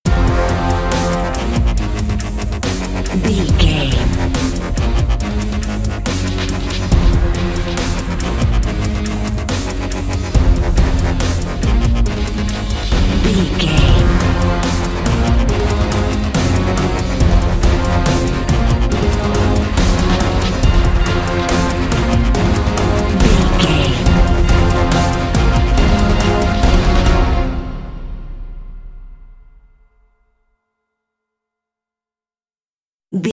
Epic / Action
Fast paced
In-crescendo
Dorian
synthesiser
drum machine
electric guitar
orchestral hybrid
dubstep
aggressive
energetic
intense
strings
synth effects
wobbles
driving drum beat